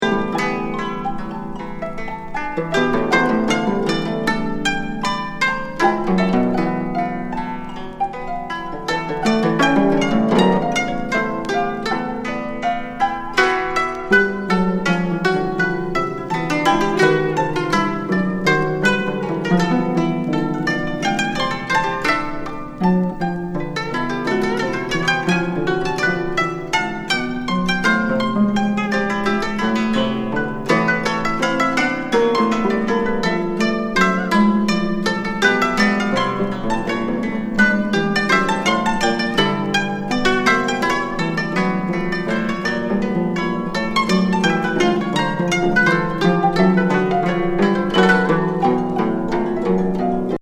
現代邦楽ミニマル